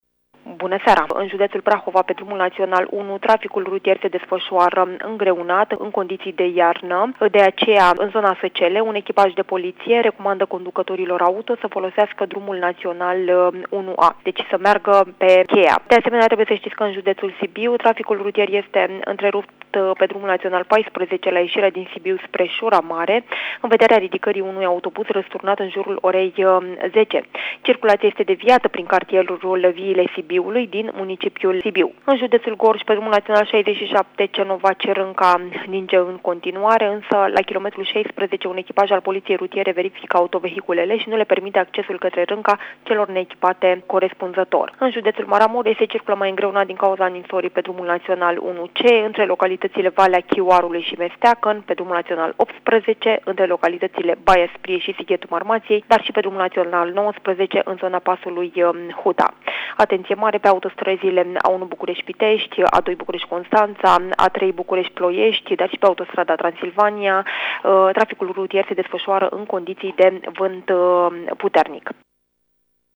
13-nov-infotrafic-nou.mp3